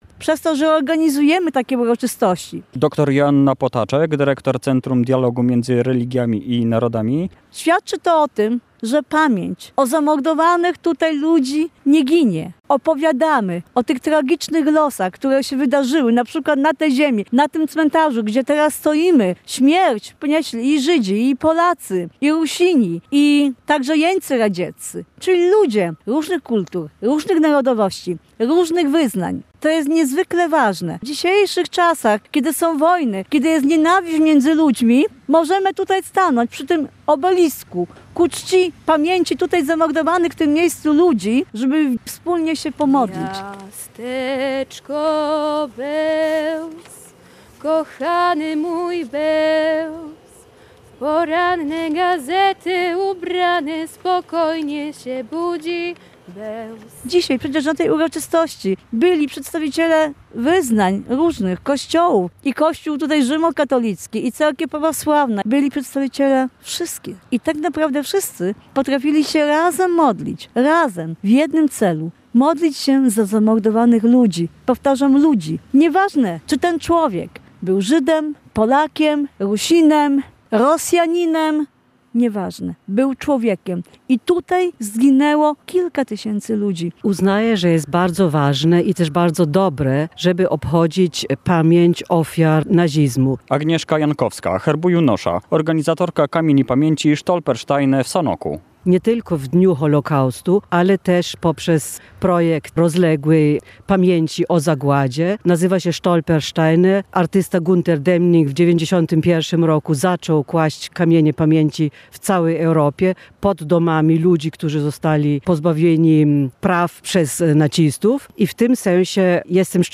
Obchody Międzynarodowego Dnia Pamięci o Ofiarach Holokaustu na Podkarpaciu [ZDJĘCIA] • Relacje reporterskie • Polskie Radio Rzeszów
Wicestarosta sanocki Damian Biskup mówił o znaczeniu edukacji i zaangażowania młodzieży: